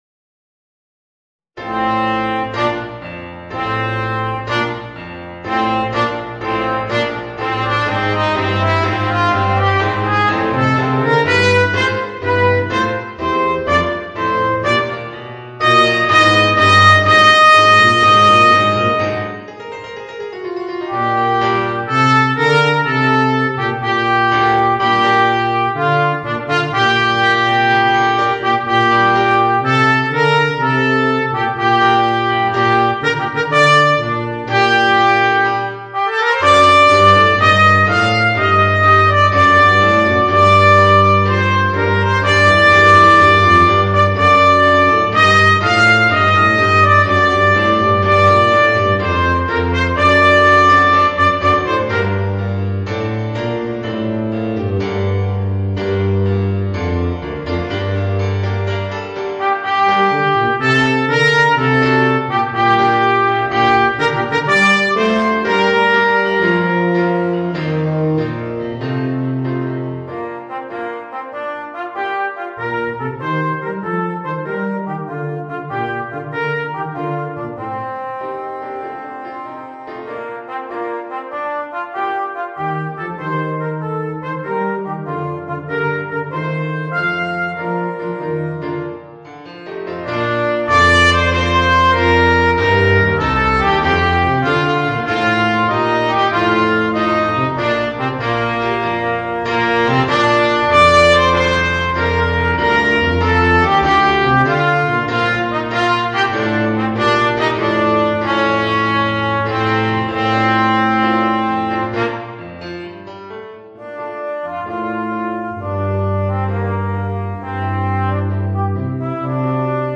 Cornet und Klavier